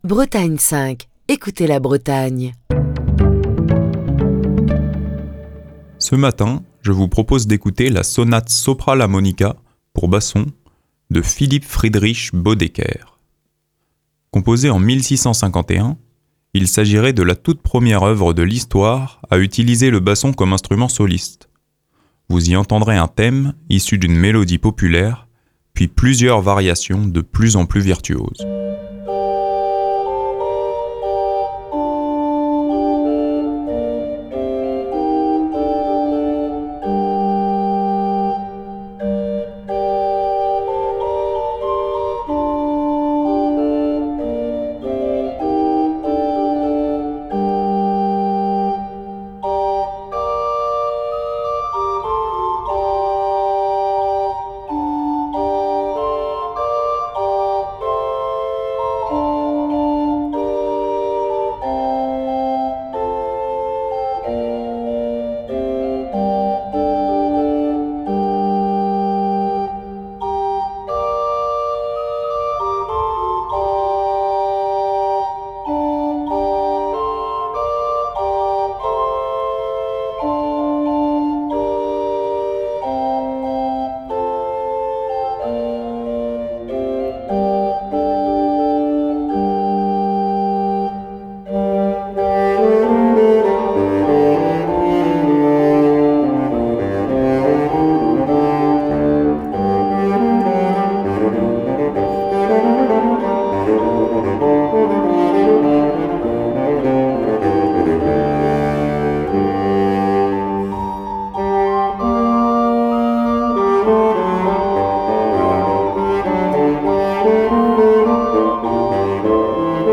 Philipp Friedrich Böddecker - Sonata Sopra La Monica, pour basson | Bretagne5